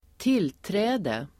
Uttal: [²t'il:trä:de]